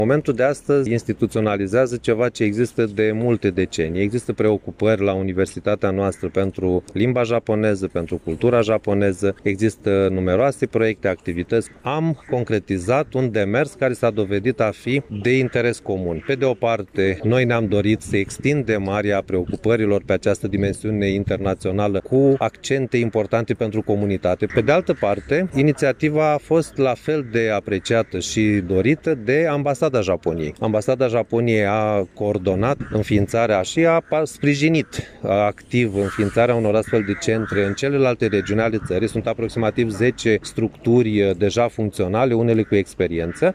Primul Centru Cultural Japonez din regiunea de Nord-Est a țării a fost inaugurat astăzi, la Iași, în prezența ambasadorului Japoniei la București.